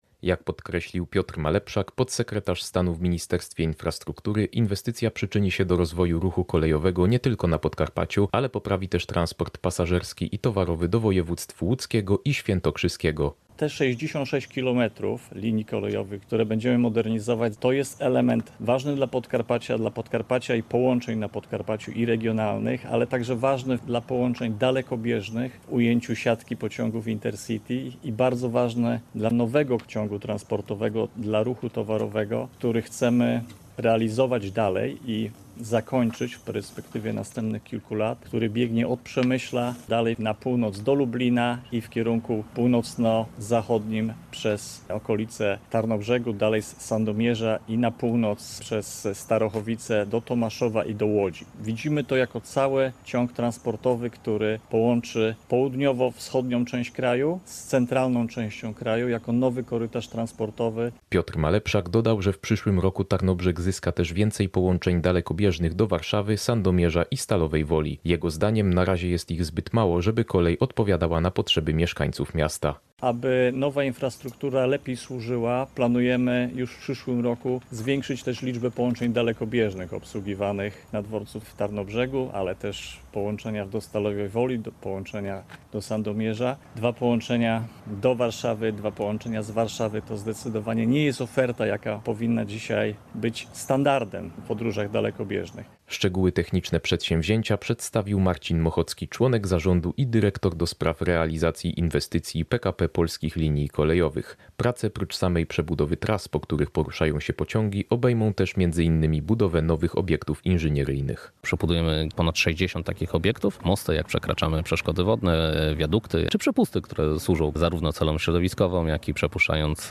Relacja